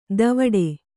♪ davaḍe